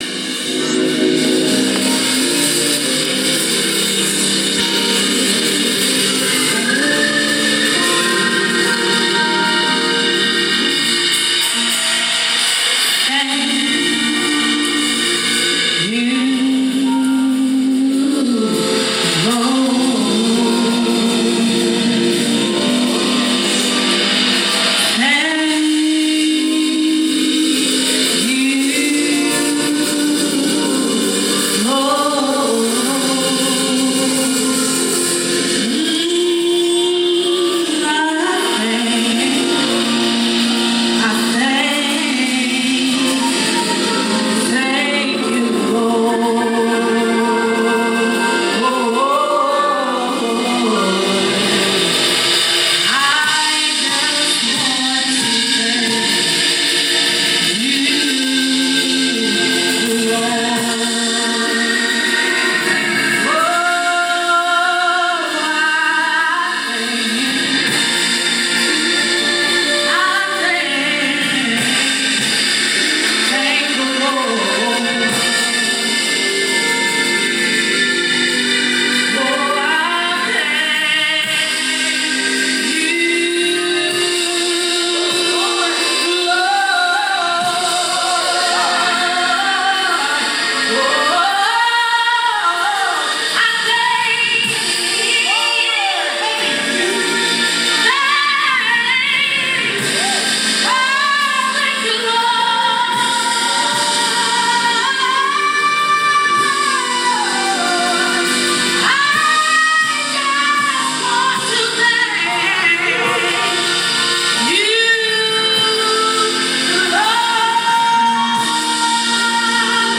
Congregational
Gospel